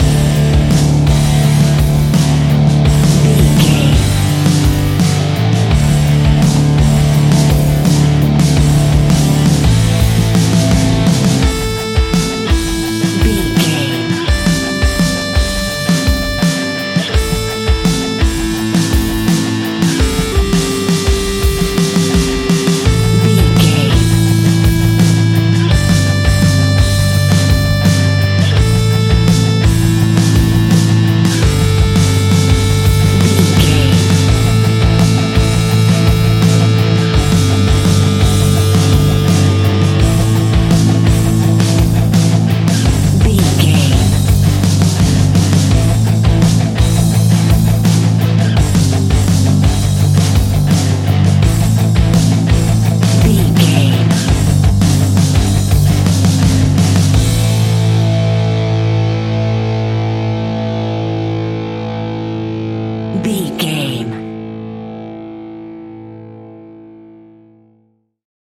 Epic / Action
Dorian
hard rock
blues rock
distortion
Rock Bass
Rock Drums
heavy drums
distorted guitars
hammond organ